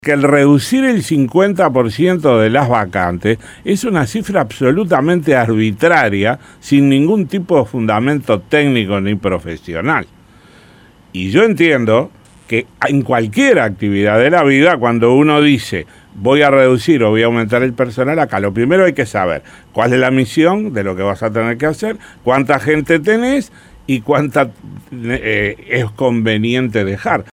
Escuche al diputado